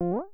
phaseJump1.wav